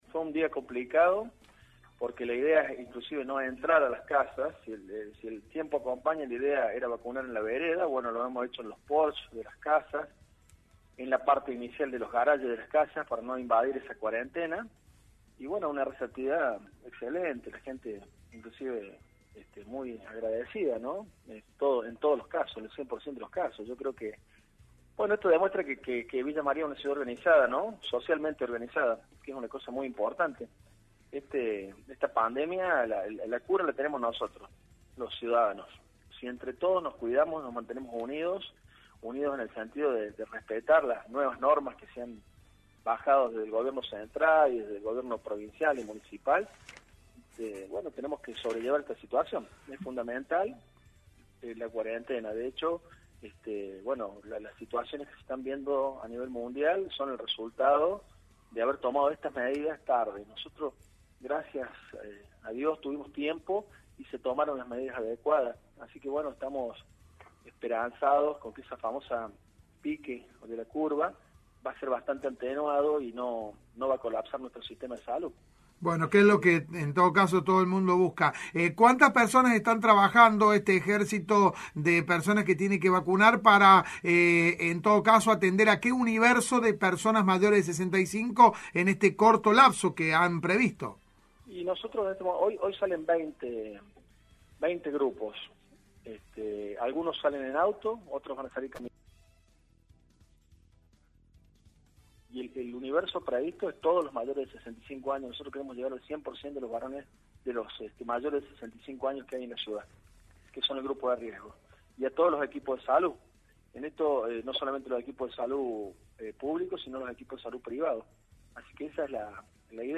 El Dr. Pedro Trecco, director de la Asistencia Pública habló con Cadena 3 Villa María.